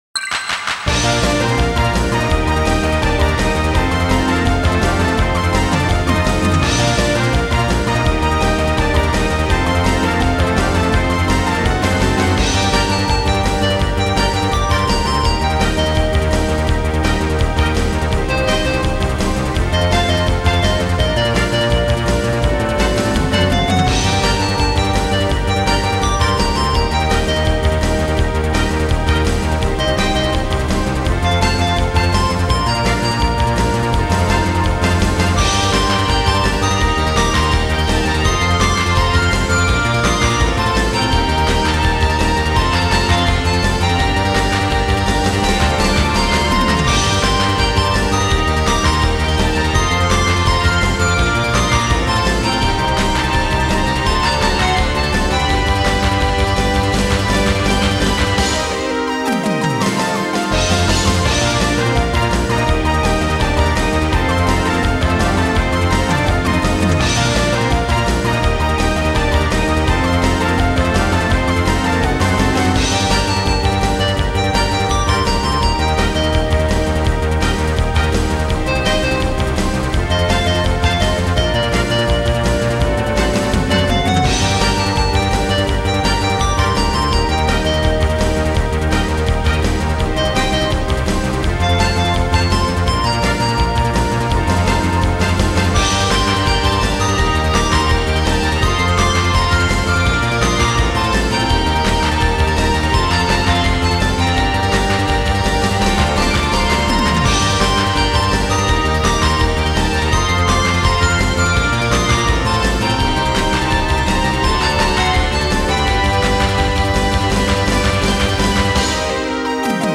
なお、掲載している音源には、EQとコンプレッサー等で若干のマスタリング処理を施してあります。
さて本曲では、ハーモニーが一気にスッキリとしたものになりましたが、これはコード理論の学習の影響で、良くも悪くも手慣れてきたことを示しています。
曲の構成もポピュラー系を土台に、フュージョン系のインストゥルメンタル音楽でよく見られた構成を踏まえながら、サビのリフレインからのフェードアウトで閉じられます。
明るい長調のイントロから、陰りのある短調のAメロへとスッと入っていき、Aメロの終わりで再び長調へ戻るかと思いきや、再び短調で進んでいく──という具合に、曲全体に渡ってメジャーとマイナーの陰影をくっきりと色づけることで、聴き手の印象を深めようとしていたことが伺えます。